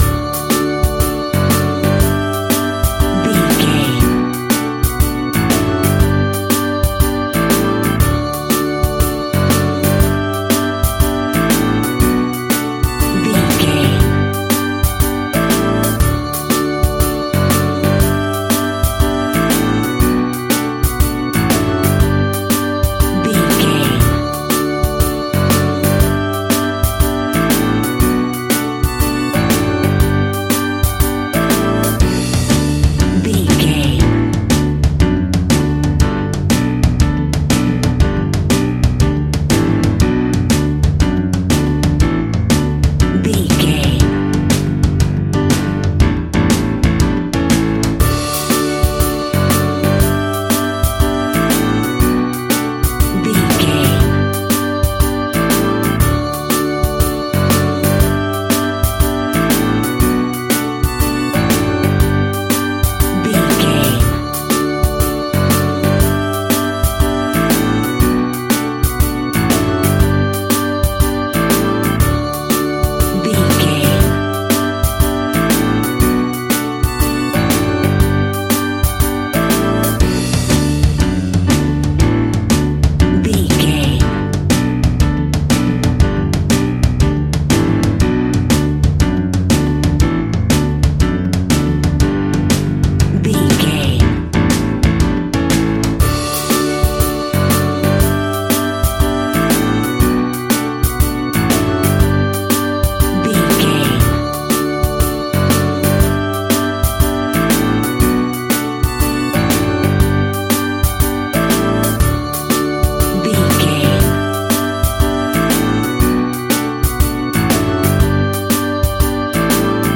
Ionian/Major
D
pop rock
fun
energetic
uplifting
cheesy
acoustic guitar
drums